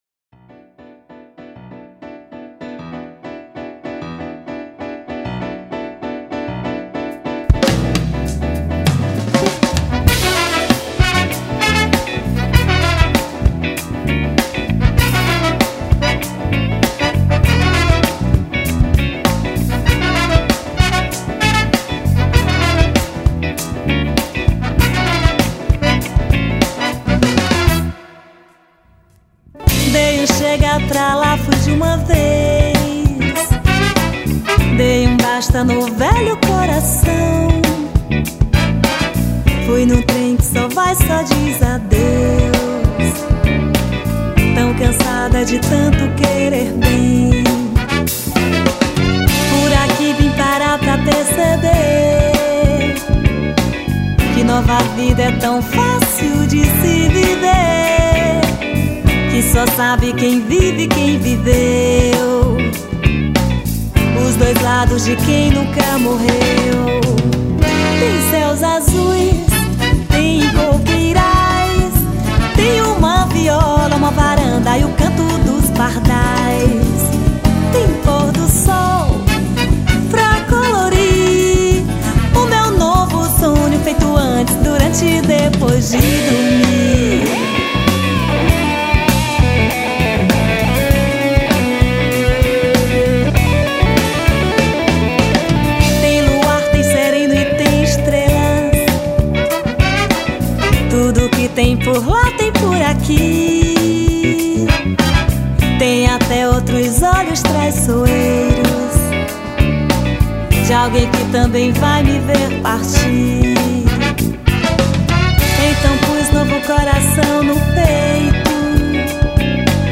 52   05:04:00   Faixa:     Rock Nacional
Guitarra, Violao Acústico 6, Baixo Elétrico 6
Teclados
Bateria
Pandeiro
Saxofone Tenor
Trombone de Vara
Trompete